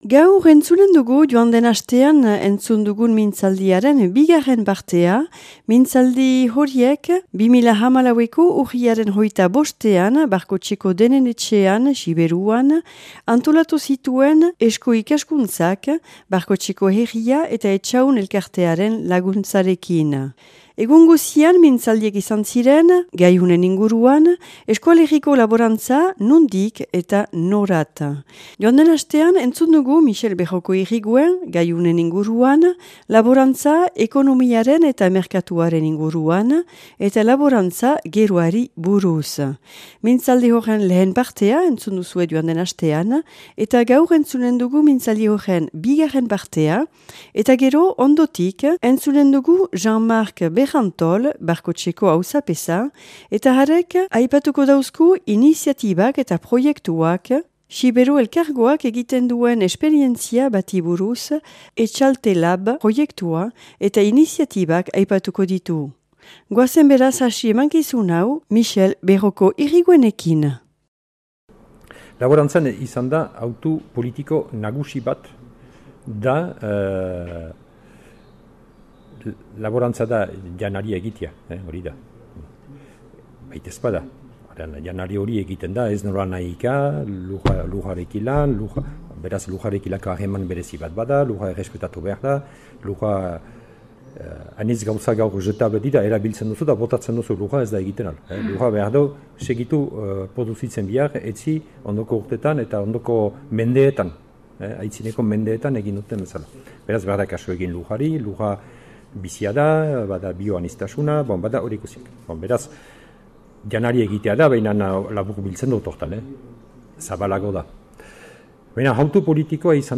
(2014. urriaren 25an grabatua Barkoxen Eusko Ikaskuntza antolaturik eta Barkotxeko herriaren eta Etxahun alkartiaren laguntzarekin).